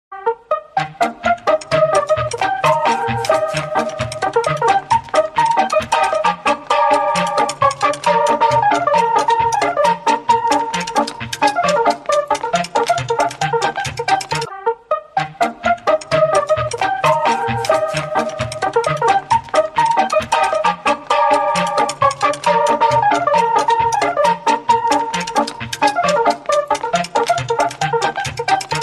• Качество: 128, Stereo
веселые
смешные
Мелодия сцены ареста в трактире